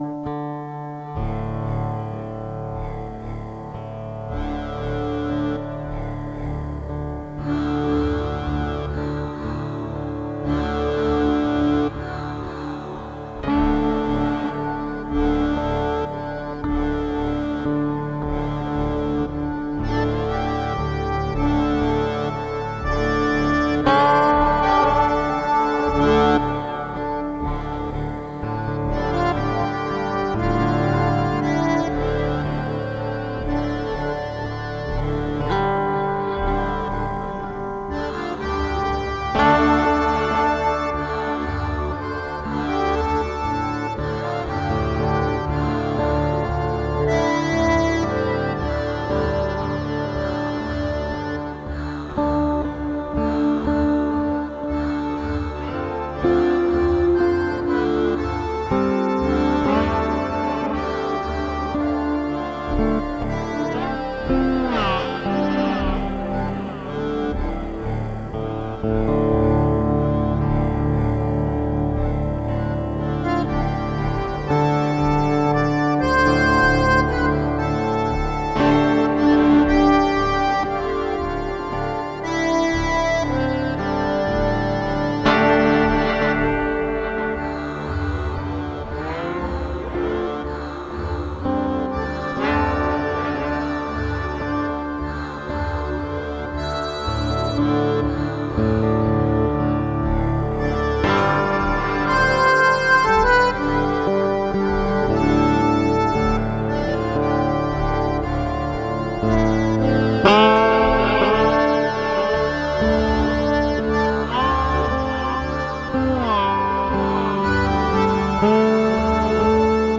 Folk relaxed.